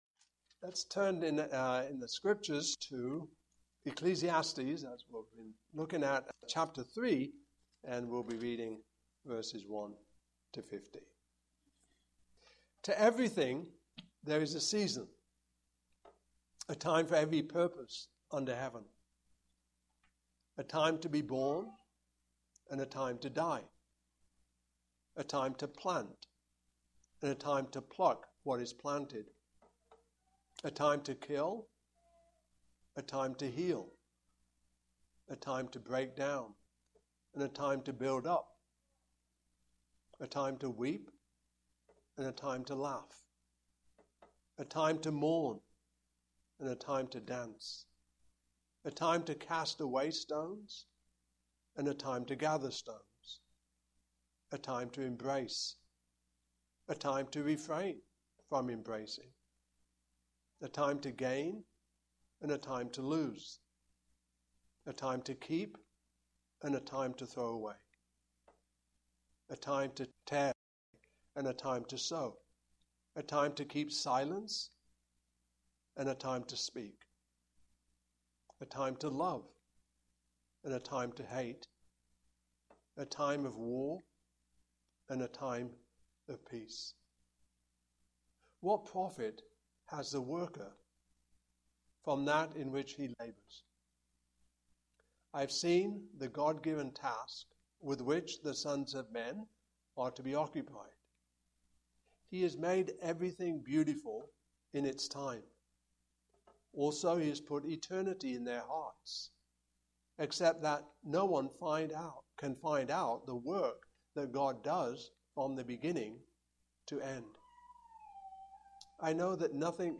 The Book of Ecclesiastes Passage: Ecclesiastes 3:1-15 Service Type: Morning Service « The Union of the Two Natures of Christ Redemption